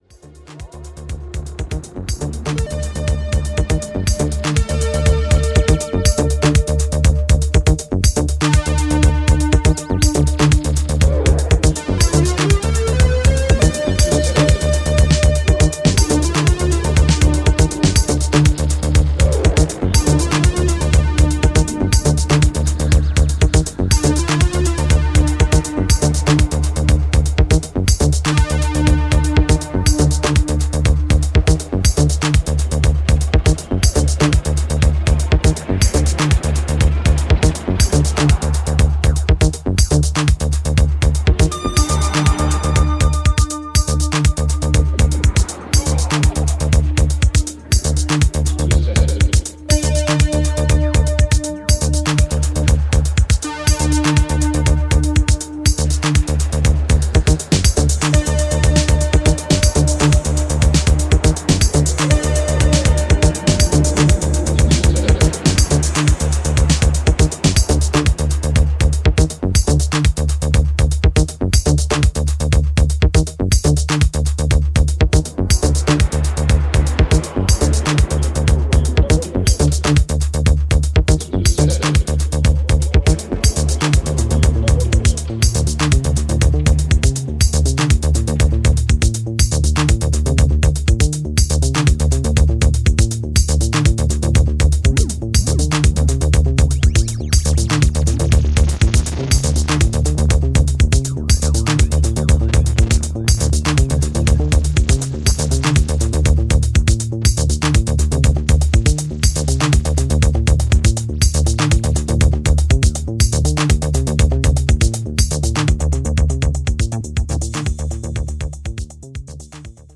Jakbeat, inspired by the early days of house music.